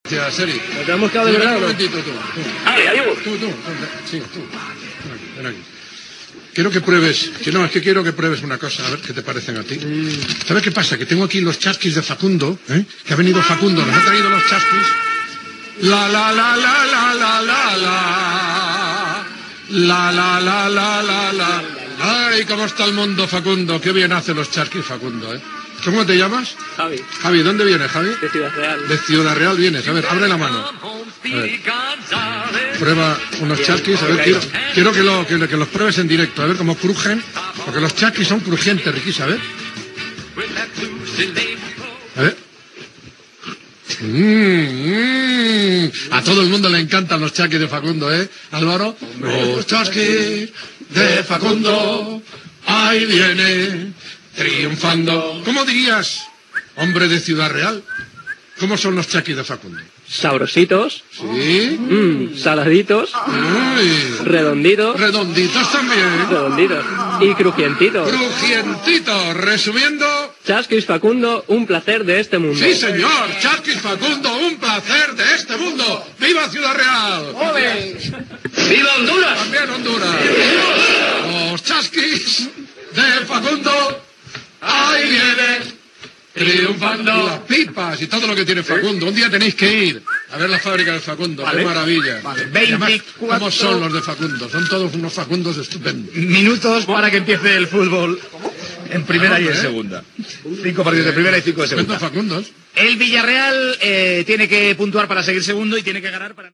Publicitat de Facundo i nombre de partits de la jornada
Esportiu